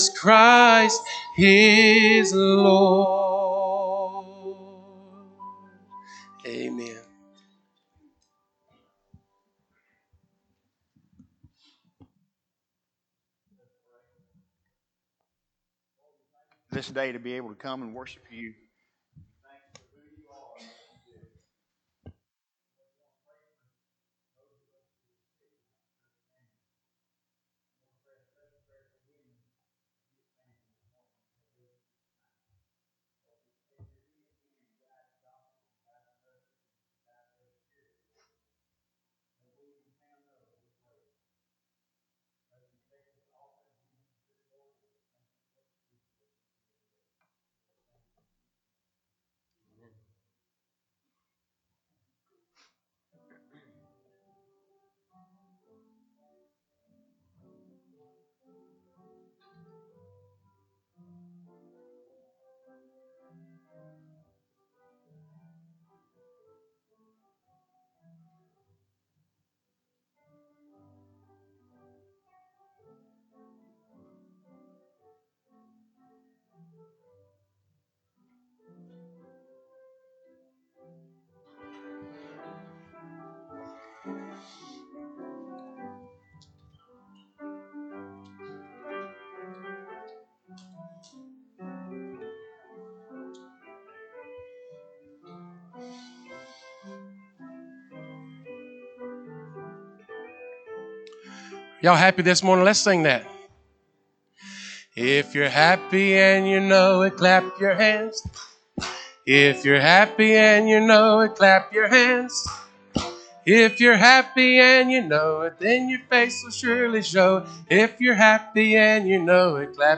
Audio Sermons: Videos of service can be seen on Facebook page | Trinity Baptist Church